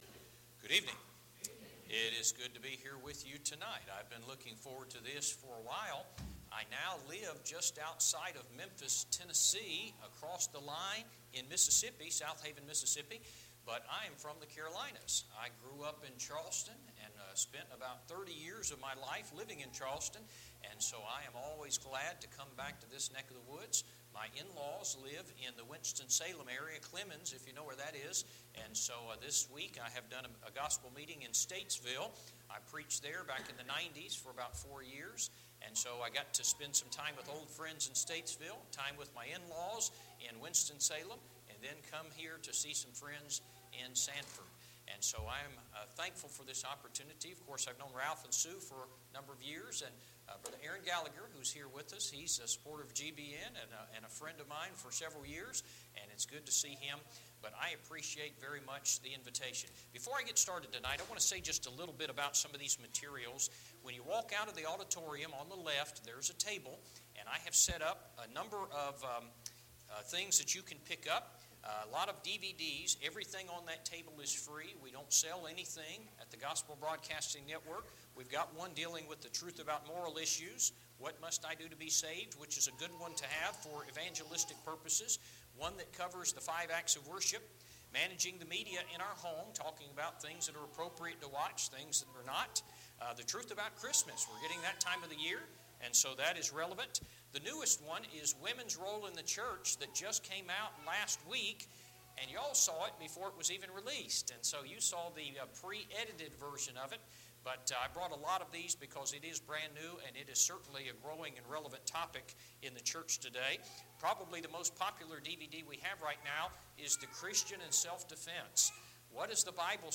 Series: 2016 Fall Gospel Meeting Service Type: Gospel Meeting